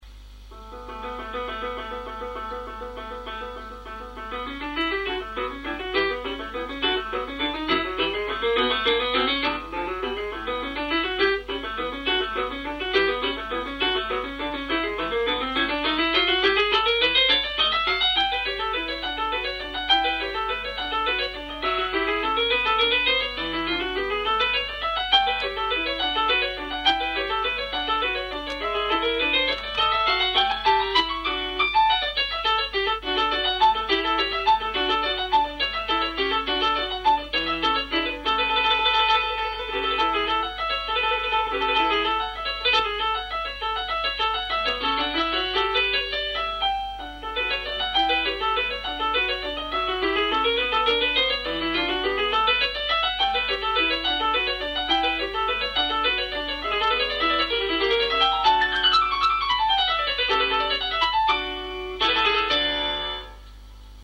Danza che i monelli ballano a Briatico nella Festa della Madonna Immacolata